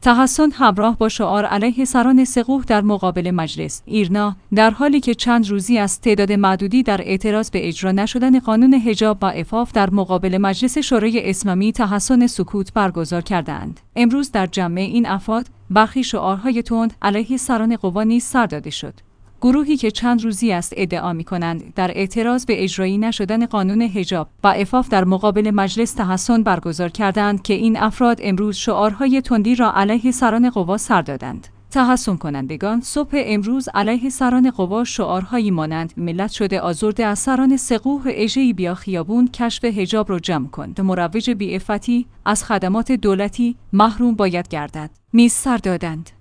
تحصن همراه با شعار علیه سران سه قوه در مقابل مجلس